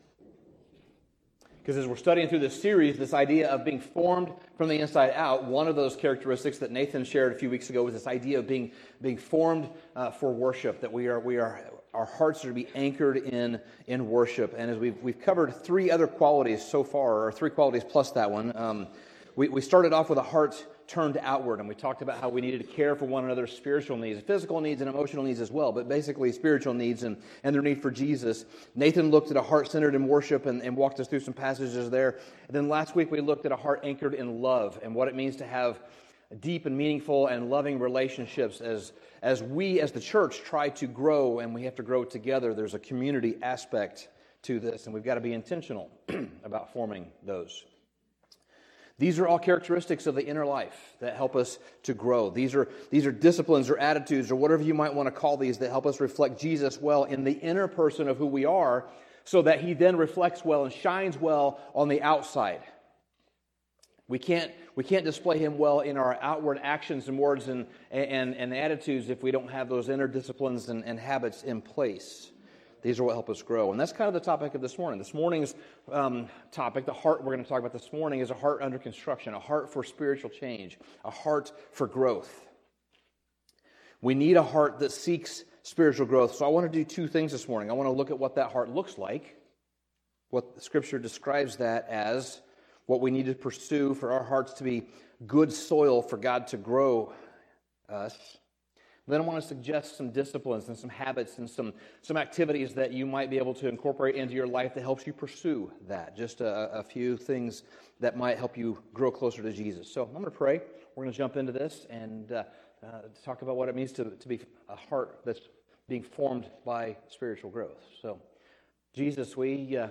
Sermon Summary Spiritual growth rarely happens by accident.